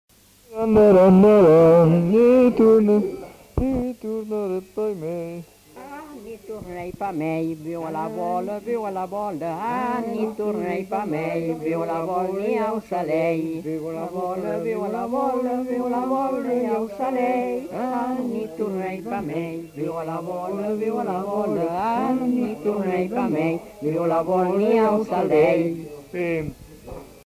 Aire culturelle : Grandes-Landes
Lieu : Luxey
Genre : chant
Effectif : 2
Type de voix : voix d'homme ; voix de femme
Production du son : chanté
Description de l'item : fragment ; 1 c. ; refr.